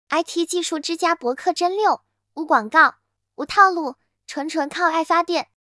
人工智能语音合成
比如 Paper2gui 的微软、抖音、阿里三家语音合成工具，像抖音这个，和剪映里的 AI 声一模一样，相信有小伙伴用的到。
测试抖音火山语音合成效果
语音合成效果.mp3